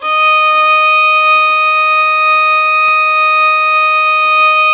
Index of /90_sSampleCDs/Roland - String Master Series/STR_Violin 4 nv/STR_Vln4 no vib
STR VLN BO0E.wav